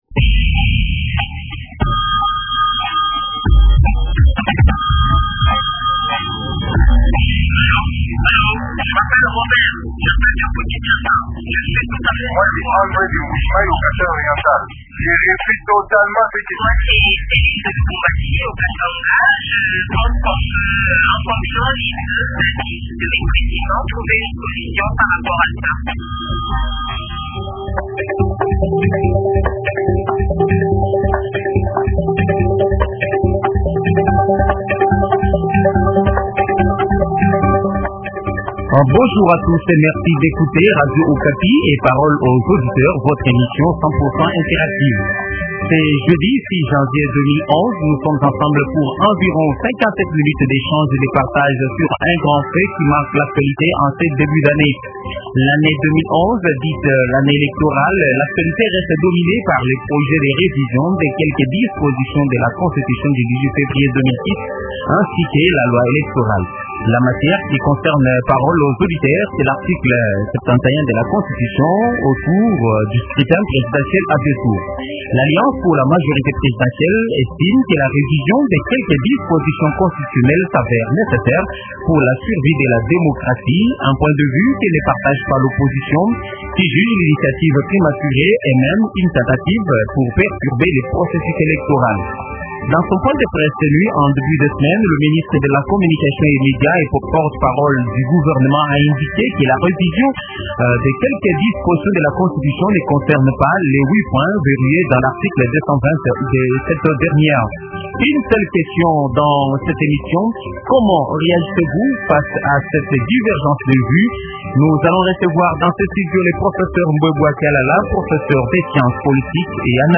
professeur des sciences politiques et analyste politique.